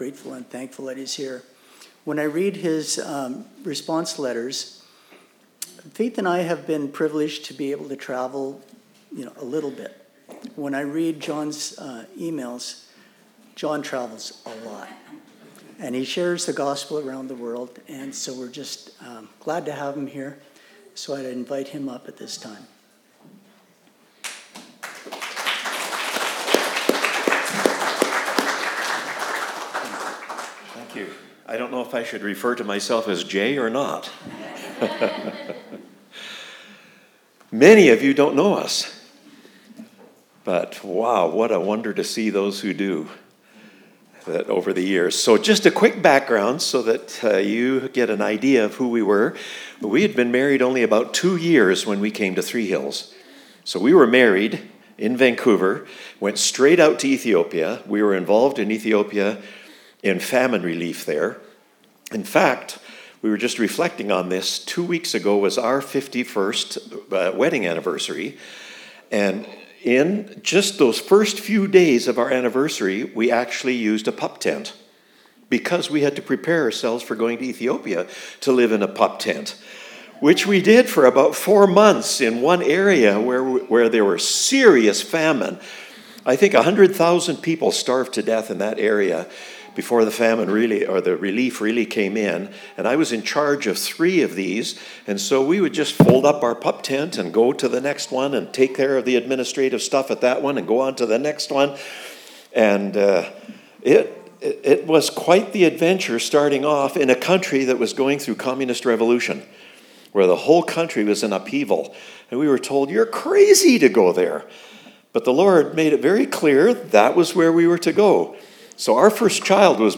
Sept 7 Sermon